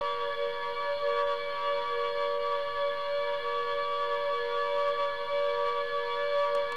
All Clear Siren